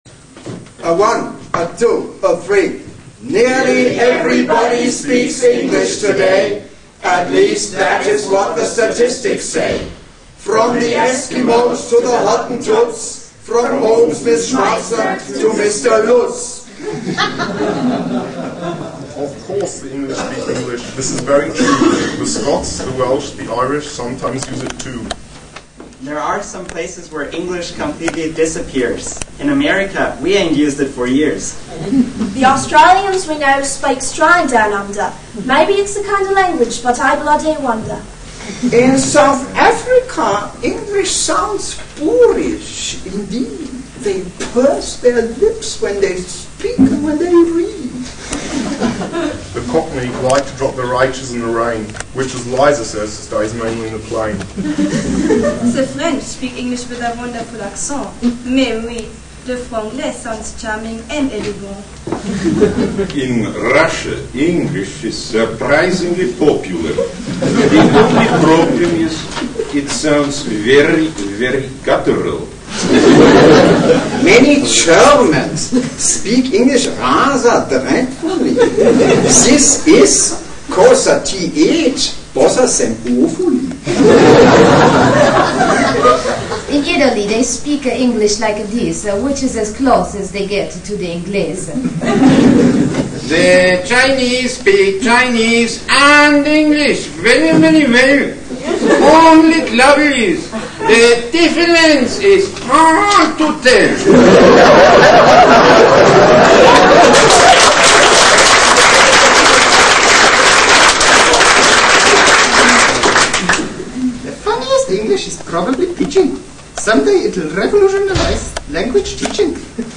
3)  The various accents of English